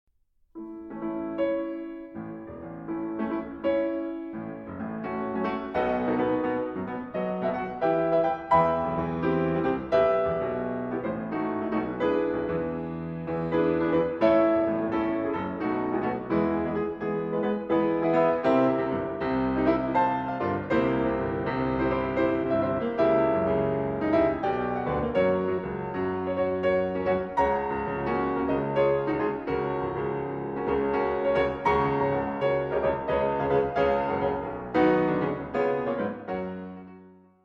Lebhaft